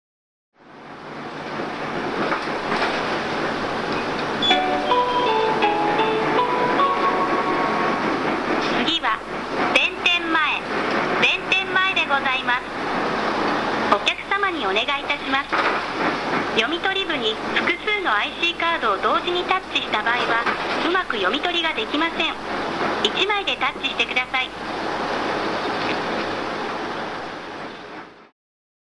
車内アナウンスコレクション
テープ時代からメロディーチャイムを使用しており何度かチャイムやアナウンサーが変わってきましたが、音声合成装置化してから一貫して同じチャイムを使用してます。